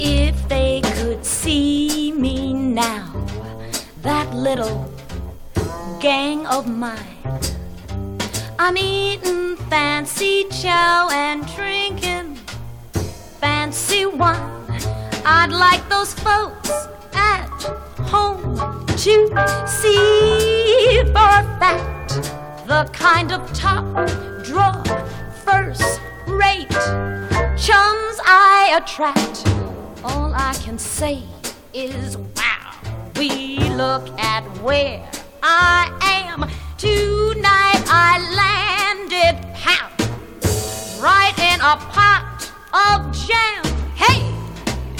本盤でも、高い表現力で魅力的なボーカルを披露。
バラエティ豊かな良盤です。
Pop, Soul　USA　12inchレコード　33rpm　Stereo